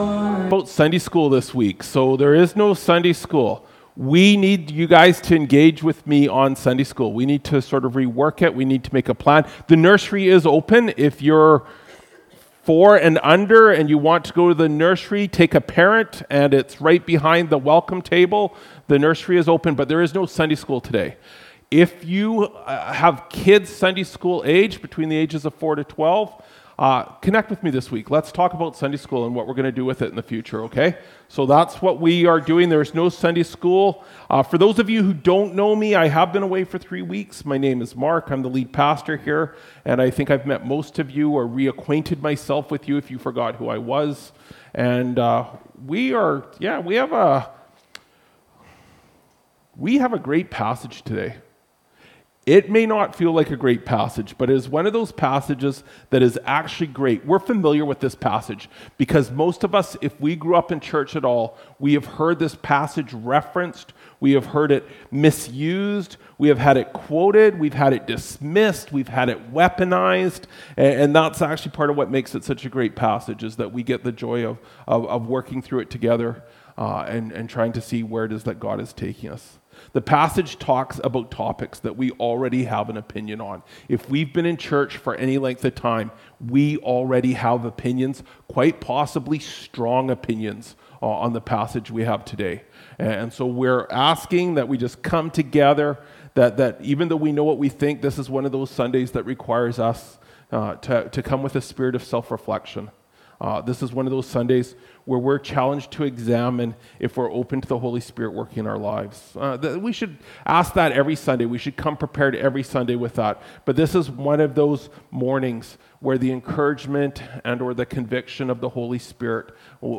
Apr 06, 2025 Sunday Mornings (1 Timothy 2:8–15) MP3 SUBSCRIBE on iTunes(Podcast) Notes Discussion Sermons in this Series This sermon was recorded in Salmon Arm and preached in both campuses.